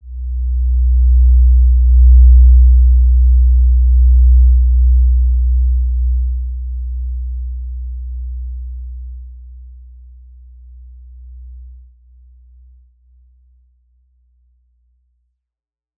Basic-Tone-B1-mf.wav